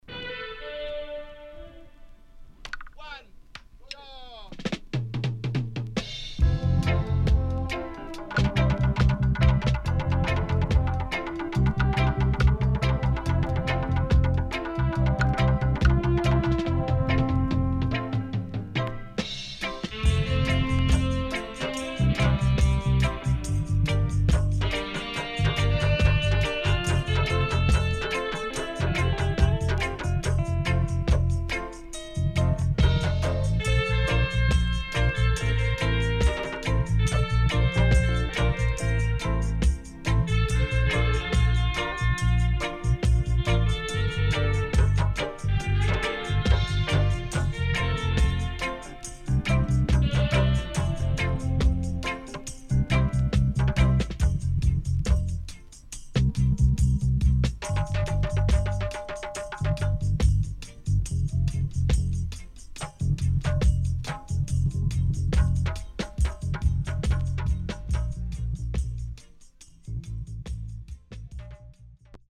HOME > DUB
SIDE A:少しチリノイズ入りますが良好です。